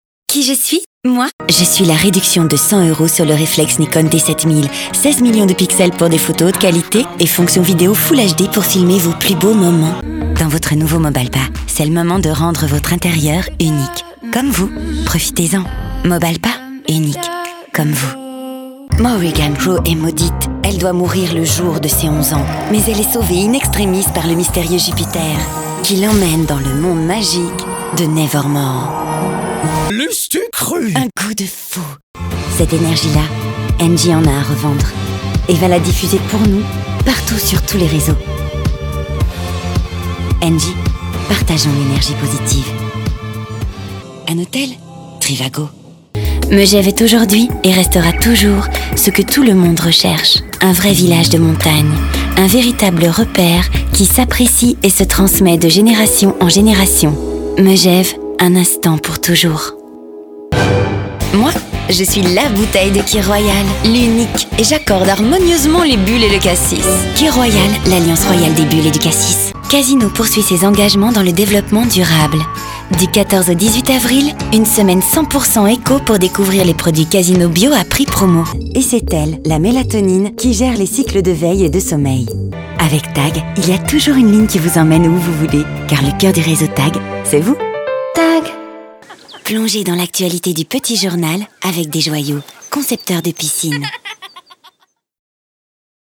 Voix off
Teaser voix-off extraits
Je suis une voix « caméléon » que j'utilise dans différents registres comme le Doublage fiction ou animation, publicité, documentaire, institutionnel et toute autre forme de support ou' elle peut s'exprimer (Lecture, théâtre).